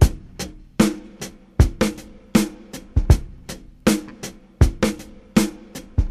• 78 Bpm Drum Loop G# Key.wav
Free breakbeat - kick tuned to the G# note. Loudest frequency: 1006Hz
78-bpm-drum-loop-g-sharp-key-iiI.wav